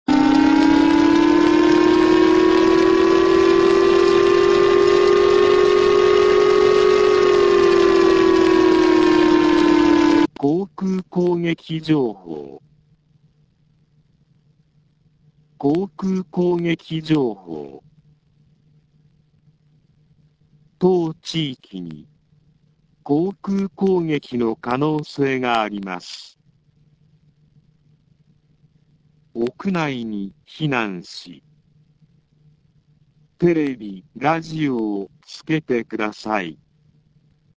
以下の情報を受信した場合、市内にある防災行政無線から、瞬時に3回繰り返し放送されます。
航空攻撃情報
放送内容は、サイレン14秒吹鳴後、「航空攻撃情報。航空攻撃情報。当地域に航空攻撃の可能性があります。屋内に避難し、テレビ・ラジオをつけて下さい。」
koukuukougeki.mp3